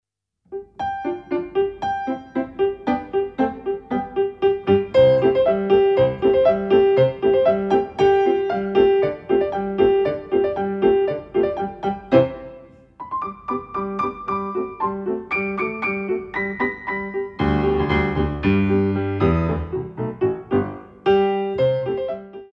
Ballet class music for children aged 5+